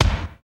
Index of /90_sSampleCDs/Roland L-CD701/KIT_Drum Kits 6/KIT_Combo Kit
KIK COMBO00L.wav